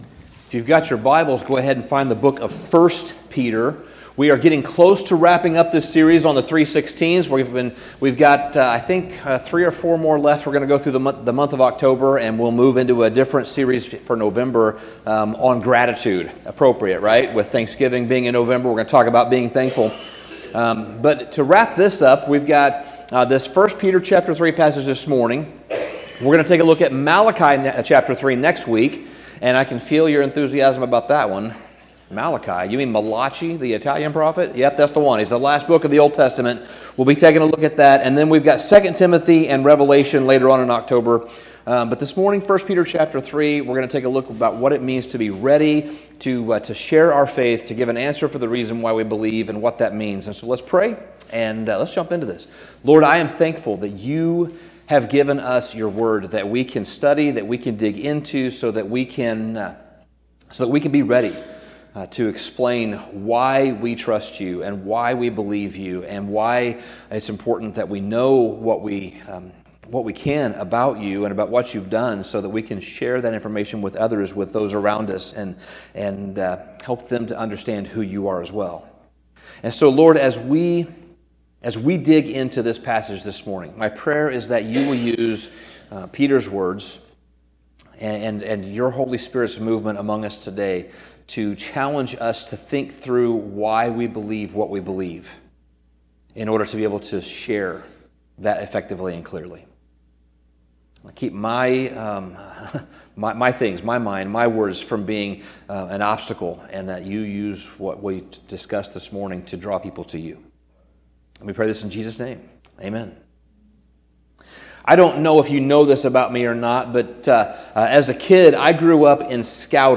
Sermon Summary In 1 Peter 3, Peter instructs us to be prepared to explain why we believe and place our hope and trust in Jesus.